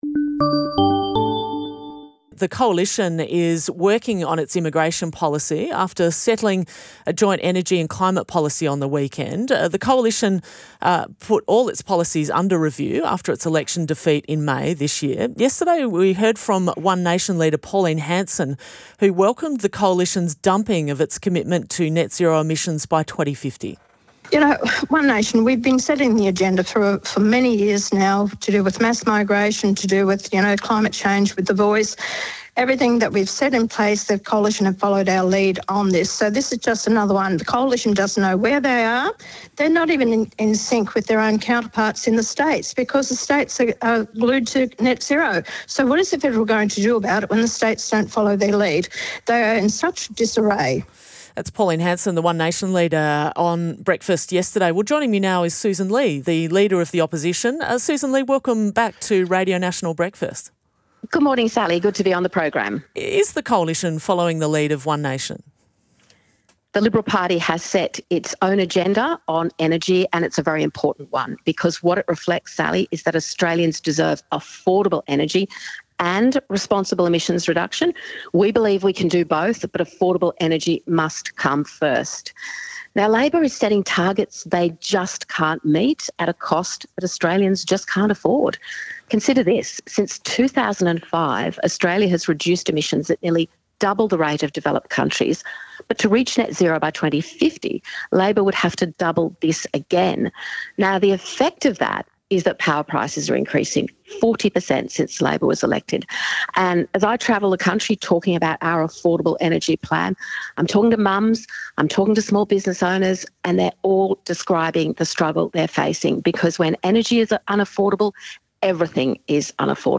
Radio National Breakfast is Australia's only daily national radio current affairs program, synonymous with agenda-setting news coverage, breaking news and a place where you will hear the most significant stories impacting the lives of all Australians wherever they live.